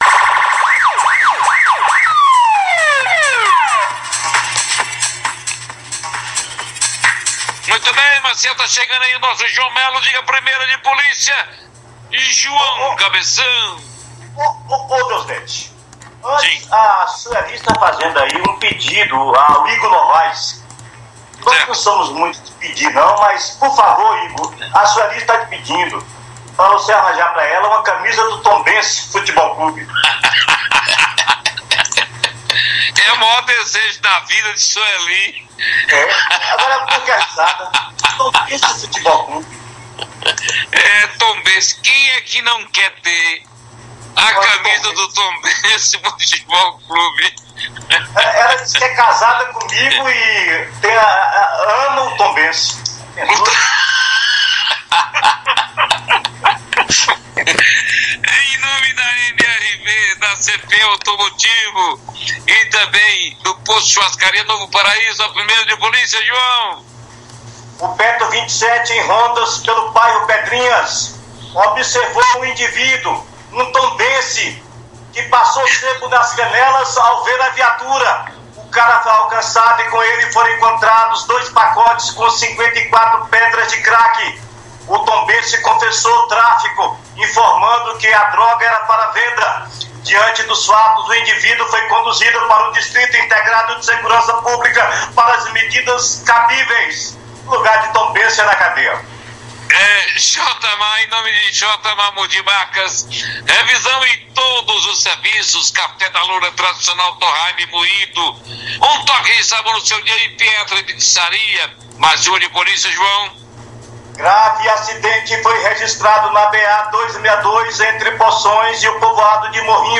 O fato é narrado pelo repórter